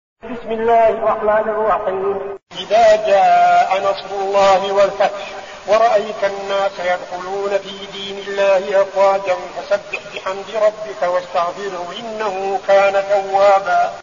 المكان: المسجد النبوي الشيخ: فضيلة الشيخ عبدالعزيز بن صالح فضيلة الشيخ عبدالعزيز بن صالح النصر